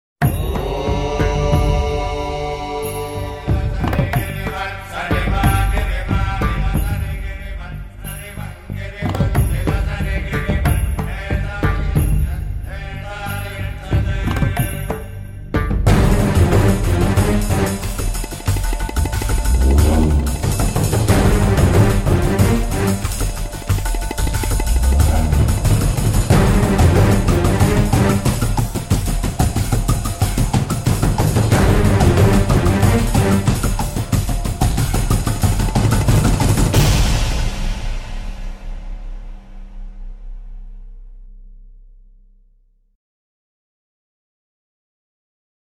Lyricist: Instrumental